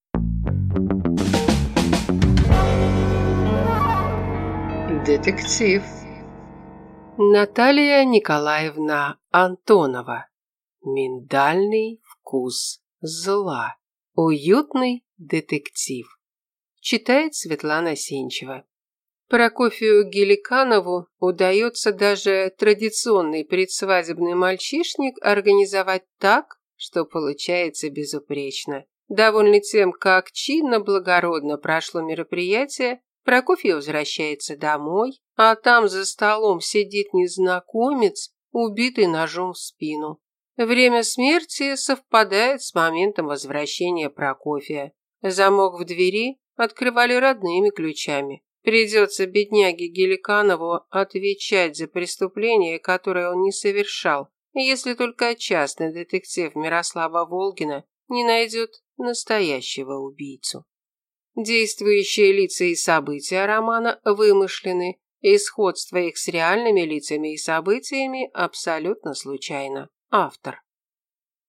Аудиокнига Миндальный вкус зла | Библиотека аудиокниг